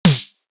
Toomp Snare 30.wav